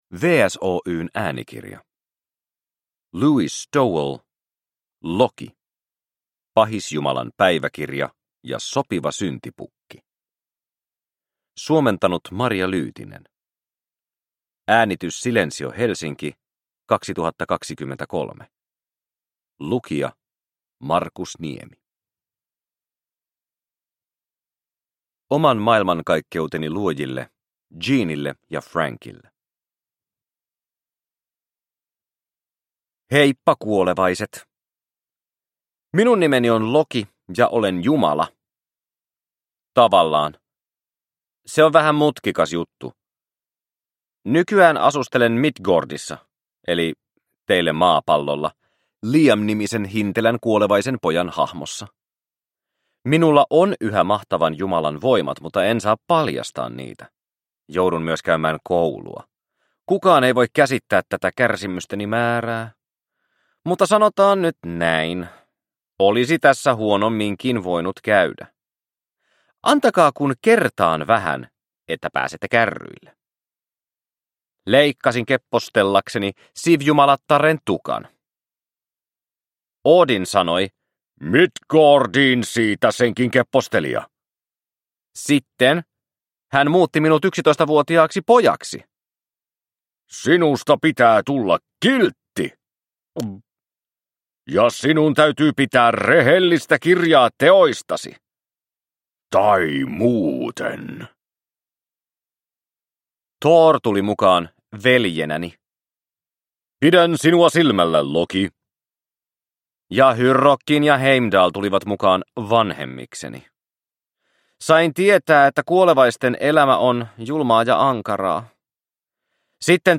LOKI: Pahisjumalan päiväkirja ja sopiva syntipukki – Ljudbok – Laddas ner